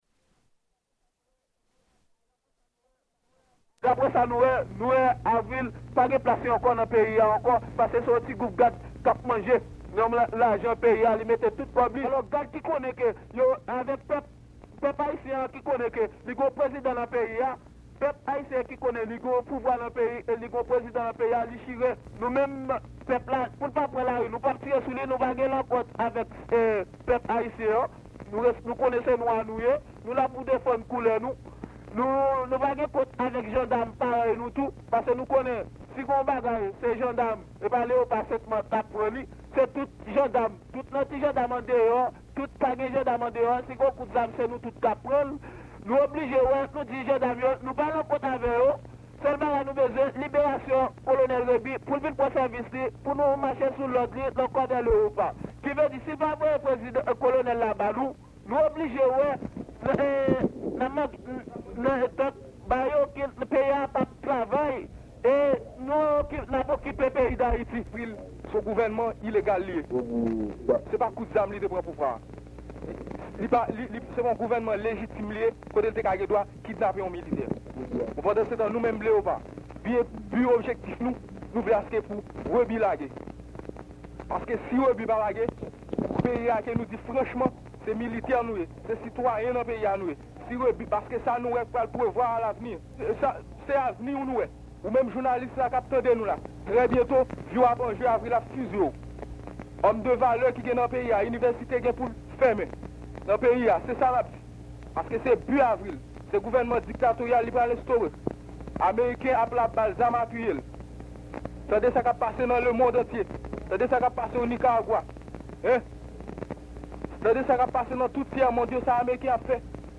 (00:02:18) Members of the Corps des Léopards come to Radio Haiti to explain their demands. They demand the release of Colonel Himmler Rébu.